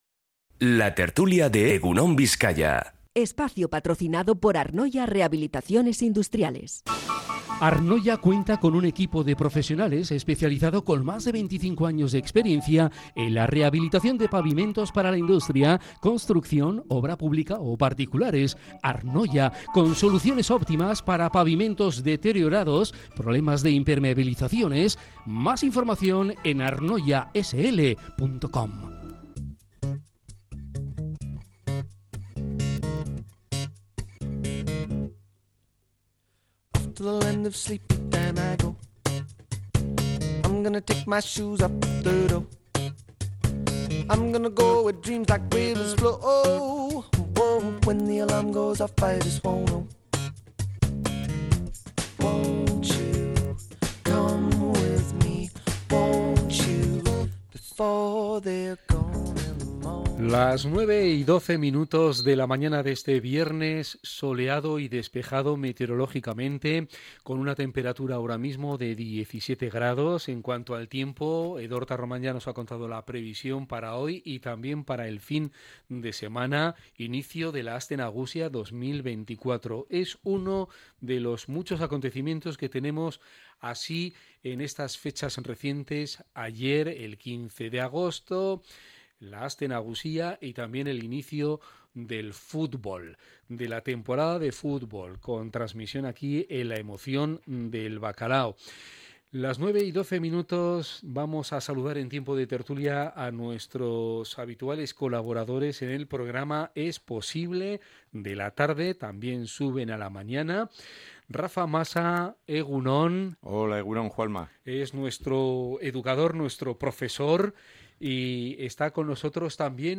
La Tertulia del verano 16-08-24